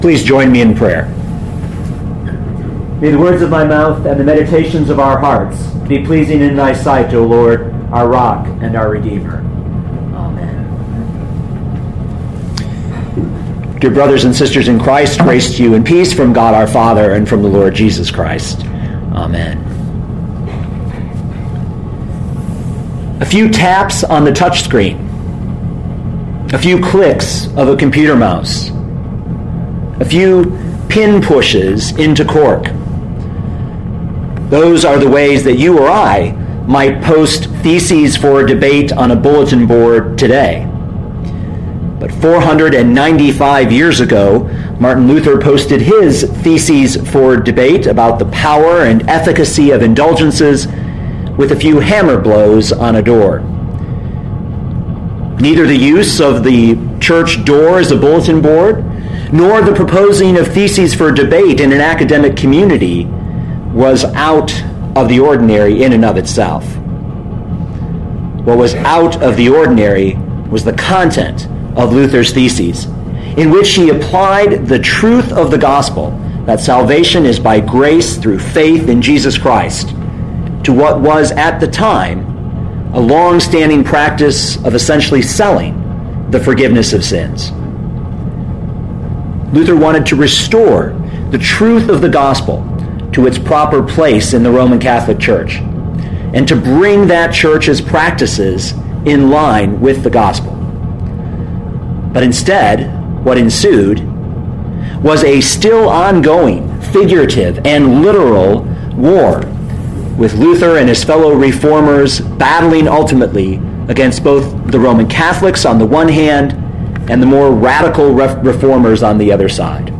2012 John 8:31-36 Listen to the sermon with the player below, or, download the audio.